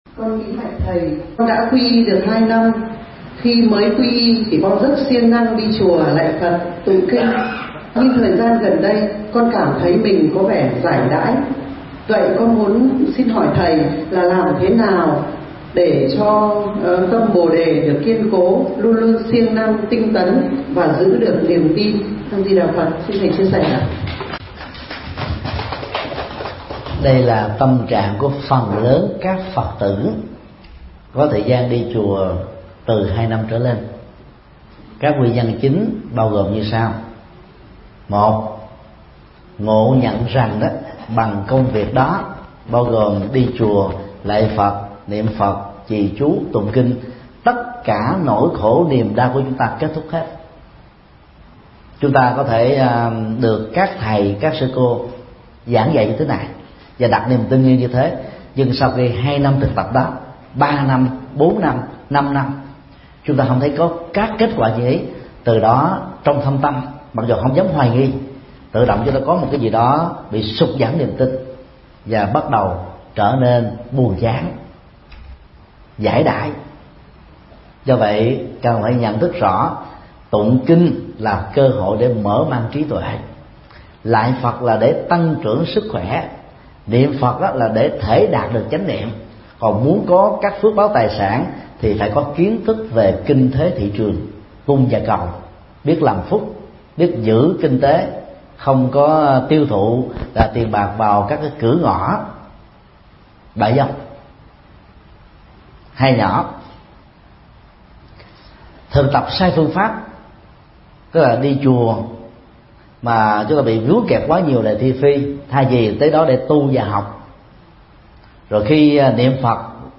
Vấn đáp: Hướng dẫn giữ vững tâm bồ đề – Thích Nhật Từ